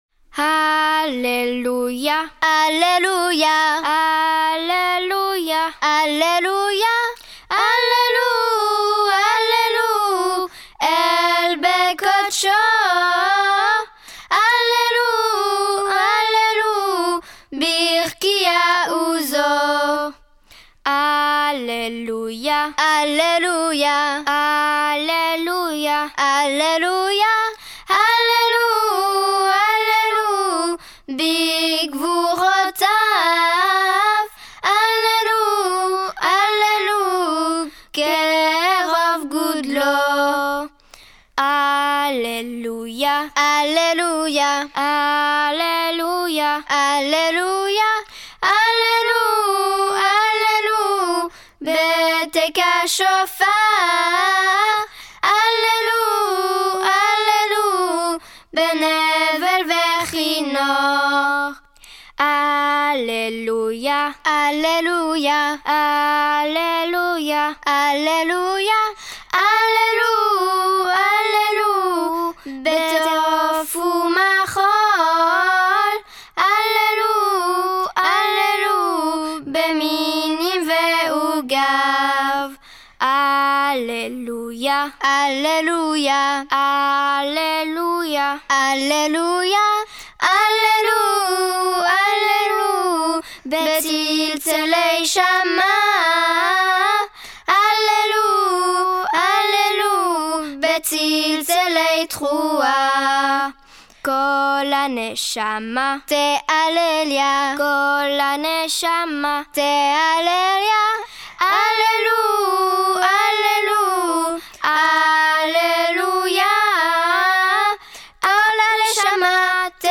Explication: EXPLICATION Le Psaume 150 est chanté à l’office du matin. Il nous invite à glorifier Dieu avec beaucoup de joie, de chants, de musique et de danses.